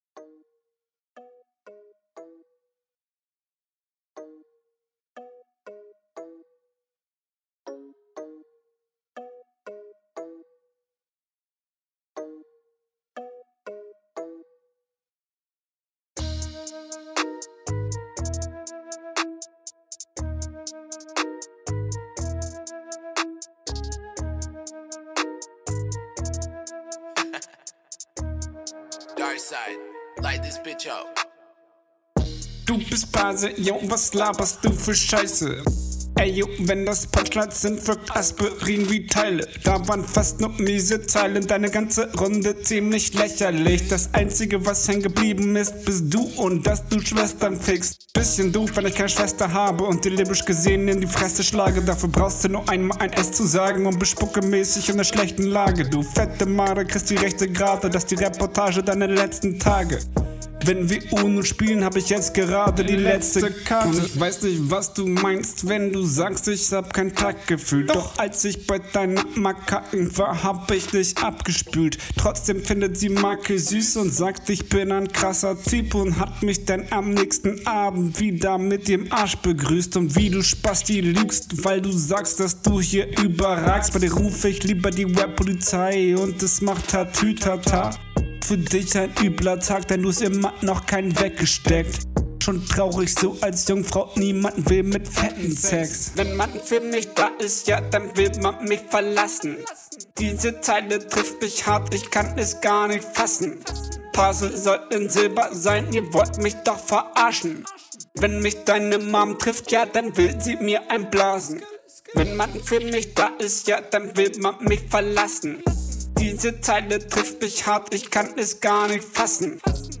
Battle Runden
hast du jede silbe einzeln aufgenommen??